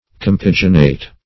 Search Result for " compaginate" : The Collaborative International Dictionary of English v.0.48: Compaginate \Com*pag"i*nate\, v. t. [L. compaginare, compaginatum.]